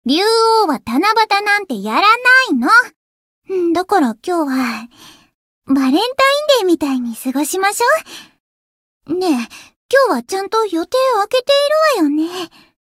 灵魂潮汐-敖绫-七夕（相伴语音）.ogg